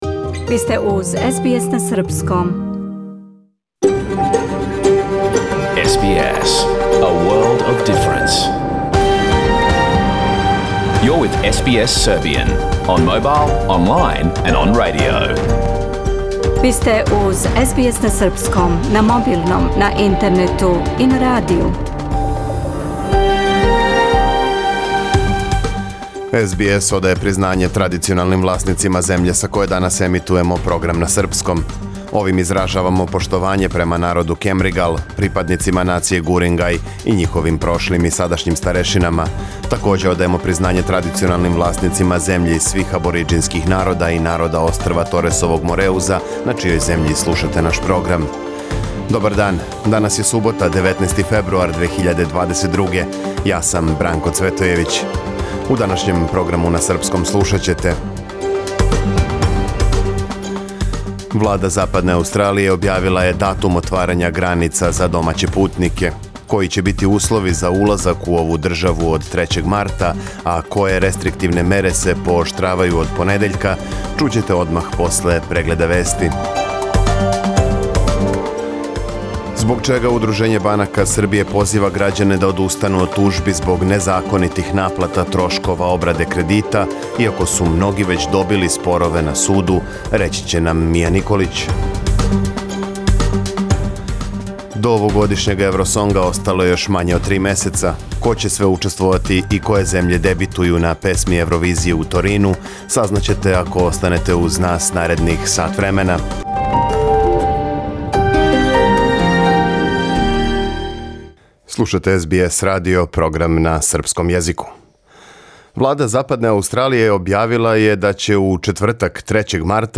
Програм емитован уживо 19. фебруара 2022. године